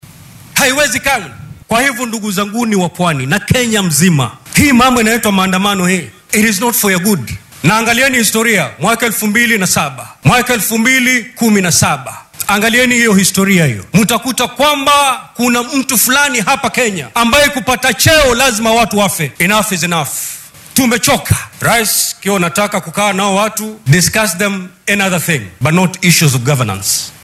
Guddoomiyaha aqalka sare ee dalka Amason Kingi ayaa madaxweynaha wadanka William Ruto ku boorriyay in uunan madaxa isbeheysiga Azimio La Umoja-One Kenya Raila Odinga iyo mas’uuliyiinta kale ee mucaaradka la gelin gorgortan salka ku haya maamulka. Kingi oo hadalkan maanta ka jeediyay ismaamulka Kwale oo uu ku sugan yahay madaxweyne Ruto ayaa dhanka kale ku baaqay in dowladda talada haysa fursad loo siiyo dhaqangelinta qorshaheedii horumarineed ee ay kenyaanka u soo bandhigtay xilligii ololaha doorashada.